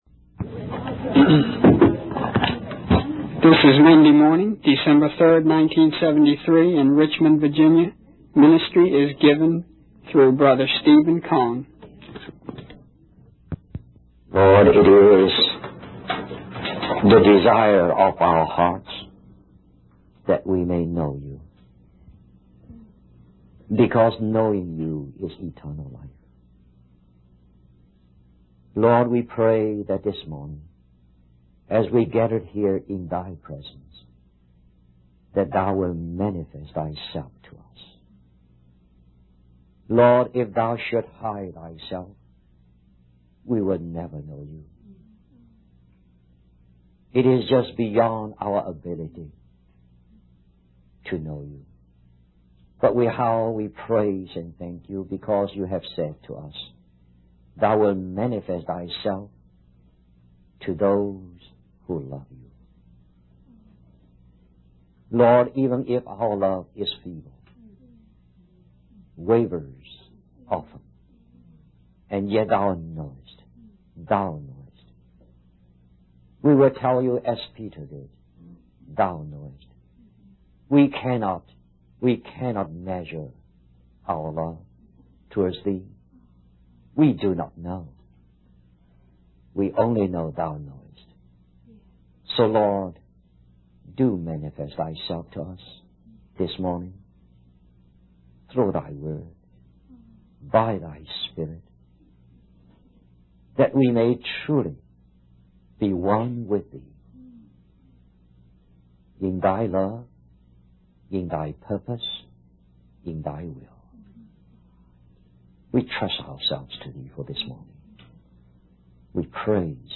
In this sermon, the speaker discusses the lack of vision and discernment among the people regarding the state of the city walls. He emphasizes the importance of understanding God's purpose while also being aware of the real situation on earth. The speaker then shares the story of Nehemiah, who secretly inspects the ruined walls of Jerusalem at night.